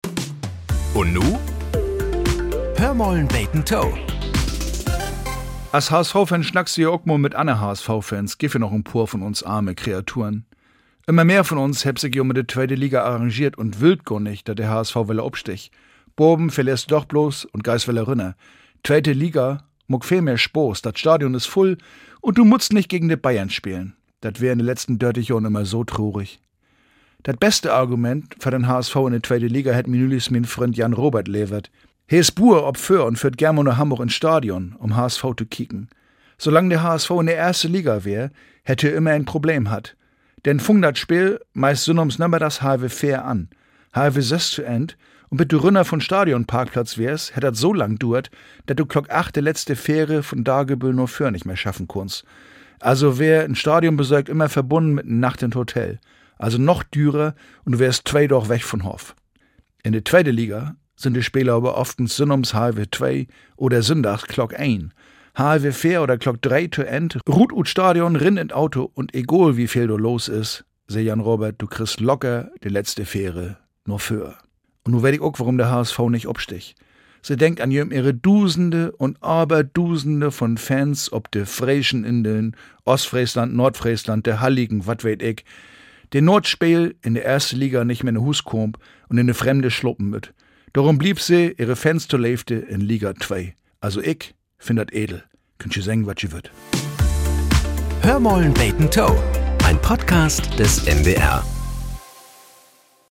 Nachrichten - 23.01.2025